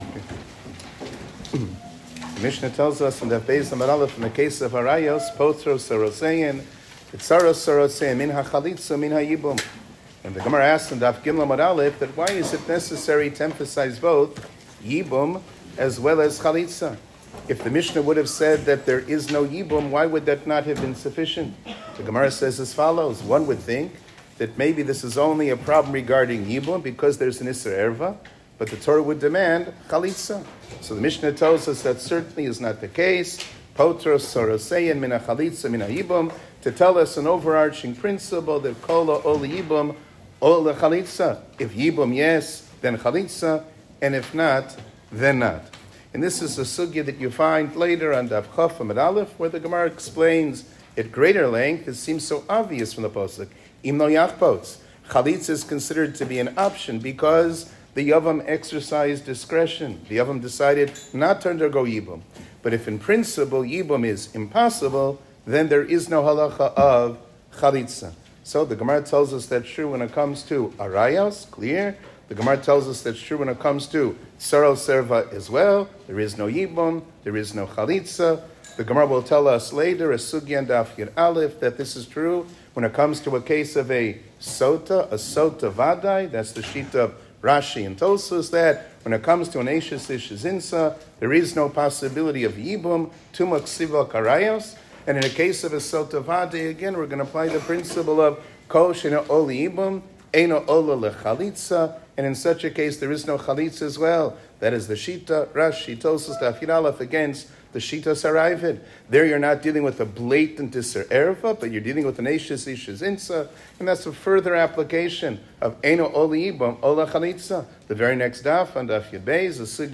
שיעור כללי - כל העולה ליבום